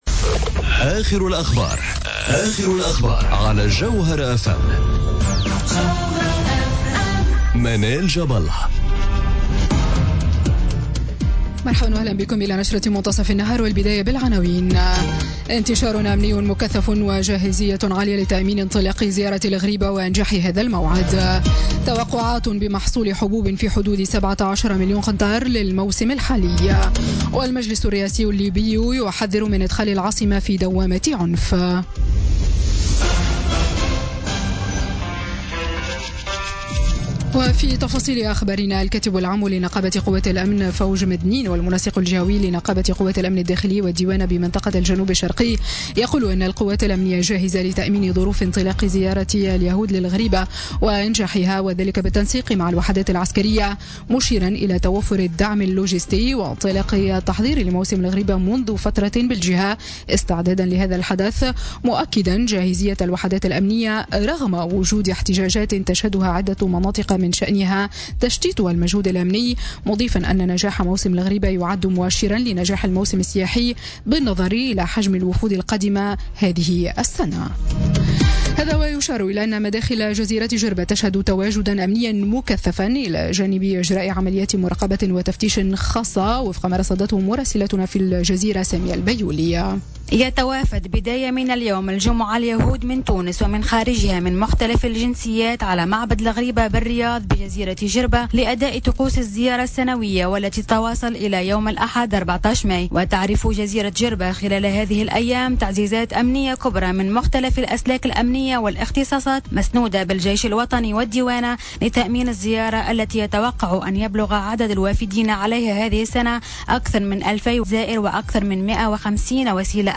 نشرة أخبار منتصف النهار ليوم الجمعة 12 ماي 2017